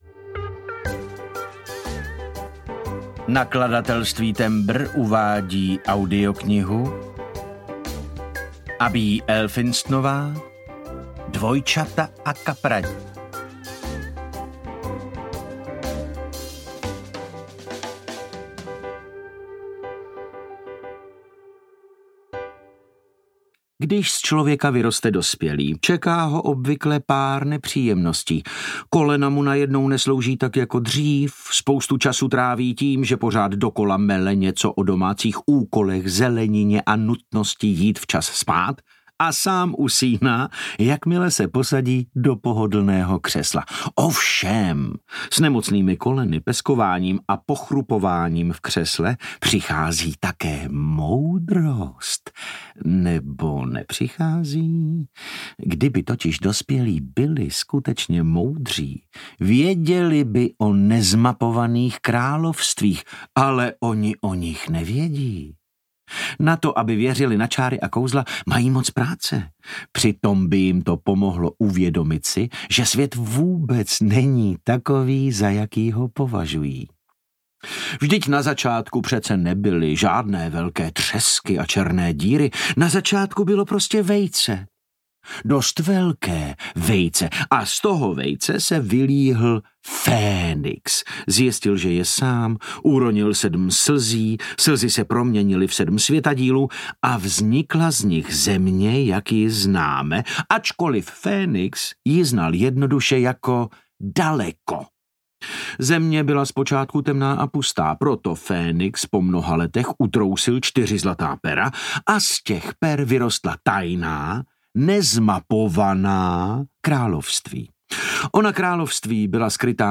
Dvojčata a kapradí audiokniha
Ukázka z knihy
• InterpretDavid Novotný